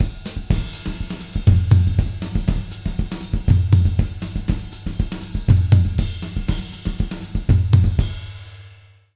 240SAMBA04-L.wav